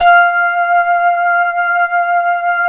Index of /90_sSampleCDs/Sound & Vision - Gigapack I CD 2 (Roland)/SYN_DIGITAL 2/SYN_Digital 5